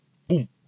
efectos de sonido cartoon " clank cartoon
标签： 卡通 漫画 声音效果 animados 卡通的声音 铮铮
声道立体声